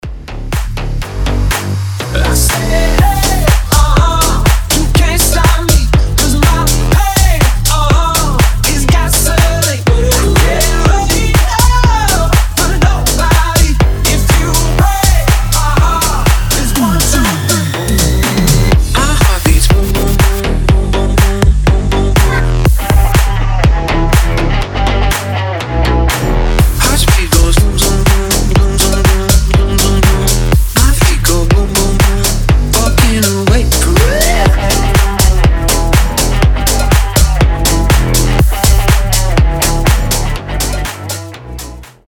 • Качество: 320, Stereo
громкие
remix
deep house
зажигательные
Club House
электрогитара
Bass House
взрывные
энергичные
Крышесносный ремикс